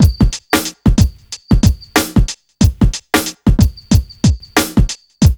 1HF92BEAT2-R.wav